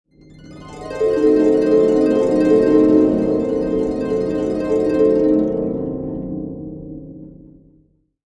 Magical Harp Sound Effect
Magical fantasy harp arpeggio, perfect for enhancing dreamy or otherworldly scenes and moments.
Genres: Sound Logo
Magical-harp-sound-effect.mp3